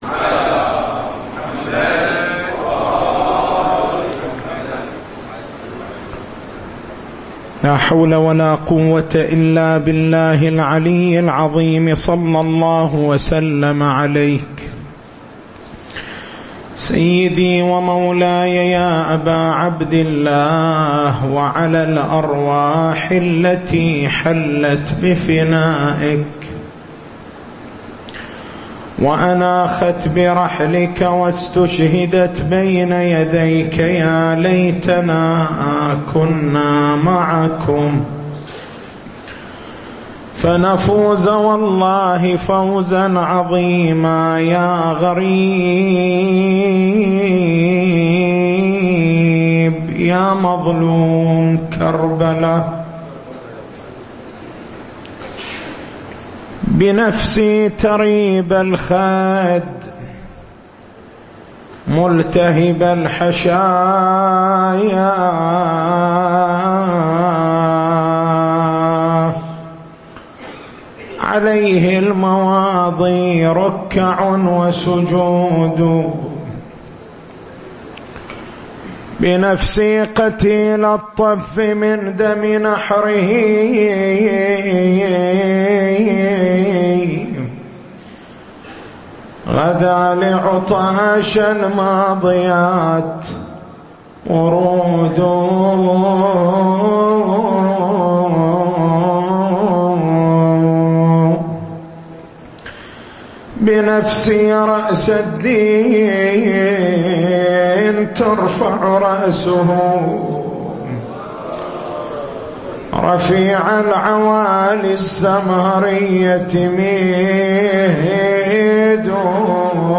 تاريخ المحاضرة: 08/09/1436 نقاط البحث: بيان مفهوم أشراط الساعة بيان مصاديق أشراط الساعة إتيان السماء بدخان مبين خروج يأجوج ومأجوج خرود دابّة الأرض التسجيل الصوتي: اليوتيوب: شبكة الضياء > مكتبة المحاضرات > شهر رمضان المبارك > 1436